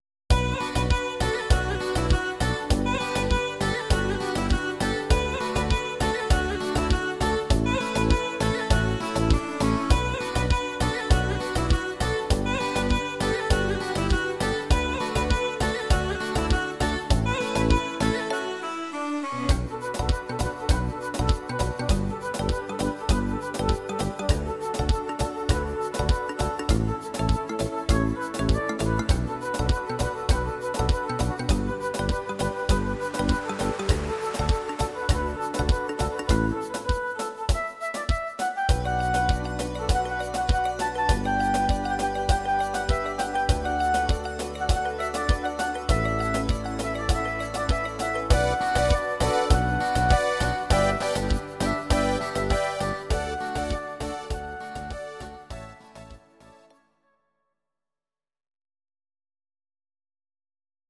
Audio Recordings based on Midi-files
Pop, Ital/French/Span, Medleys, 2010s